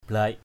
/blaɪʔ/ (đg.) chẻ = fendre. blac kayau b*C ky~@ chẻ gỗ. thei ndom pathaoh langik blac nyu E] _Q’ F%_E<H lz{K g*C v~% ai nói khống trời đánh (chẻ) nó....